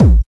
1809L BD.wav